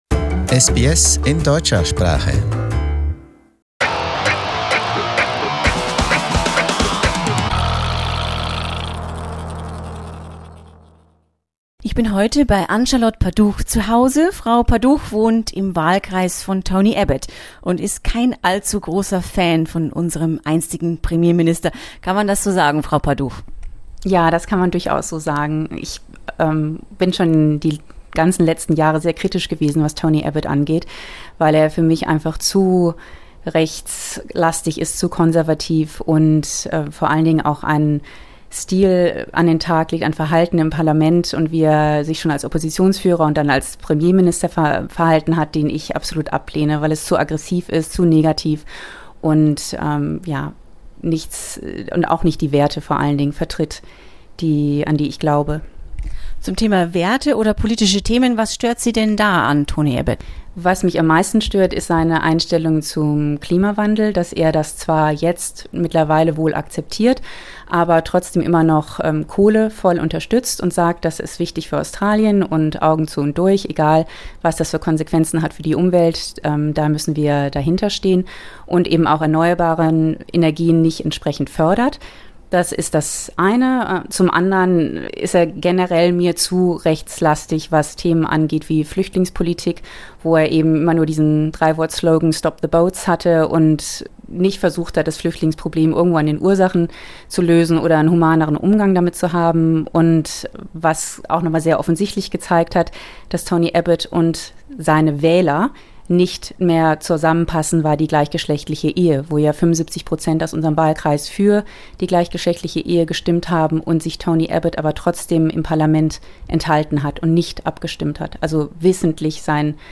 mit einer deutschen Aktivistin gesprochen